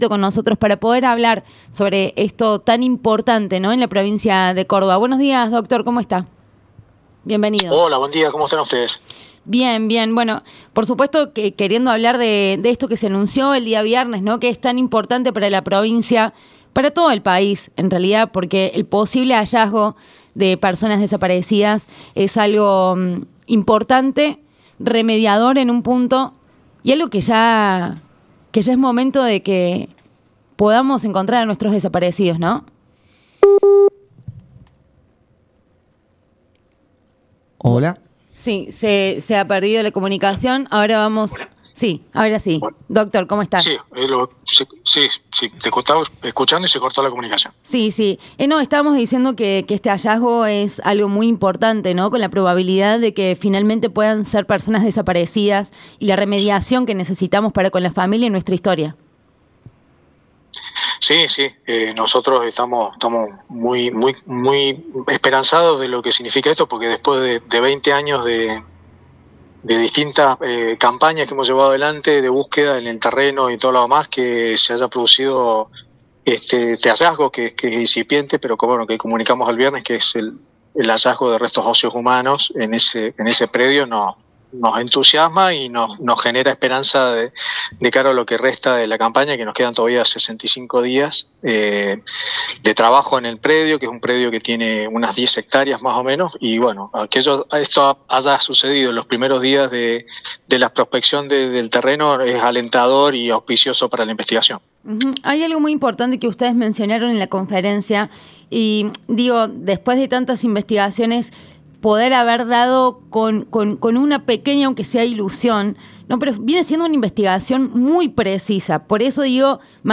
habló con Radio Nexo sobre el reciente hallazgo de restos óseos en el Centro Clandestino de Detencion “La Perla”. El descubrimiento reaviva la esperanza de avanzar en la búsqueda de personas desaparecidas durante la dictadura, en una campaña que lleva más de 20 años de trabajo e investigación.